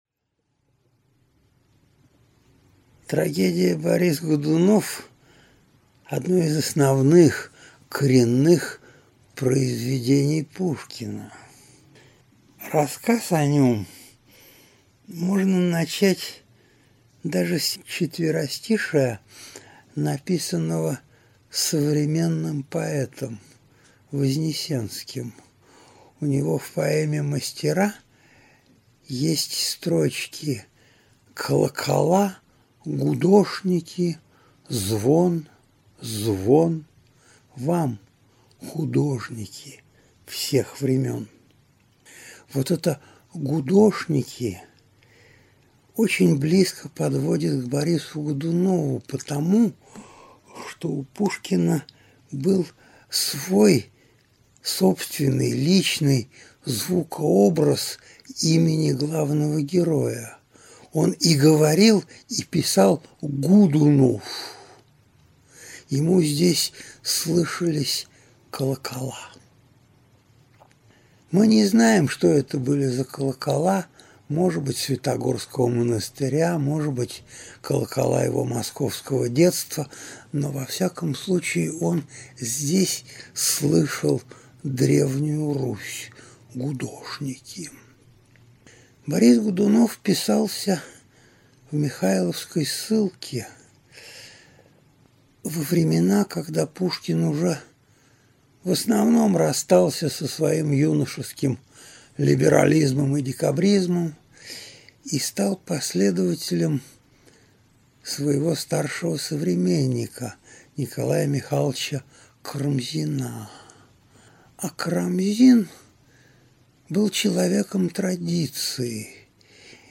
Aудиокнига Трагедия «Борис Годунов» в истории и культуре Автор Виктор Листов.